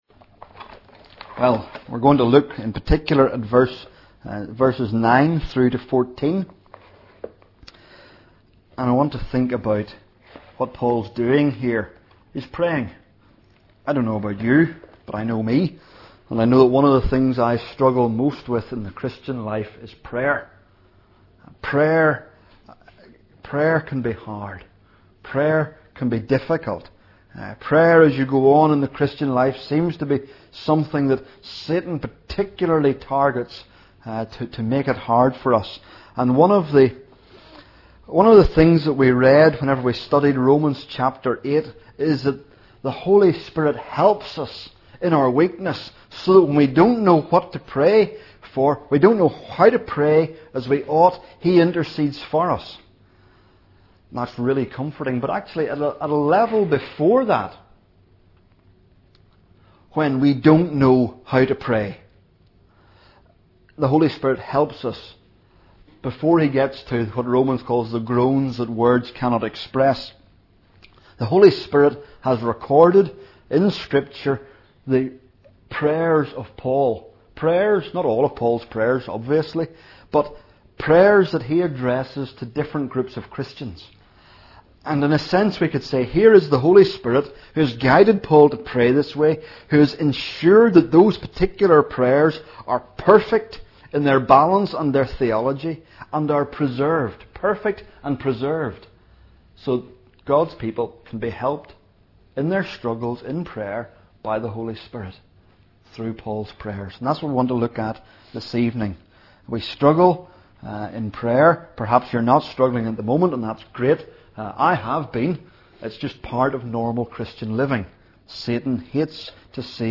Colossians | Sermons at New Life Fellowship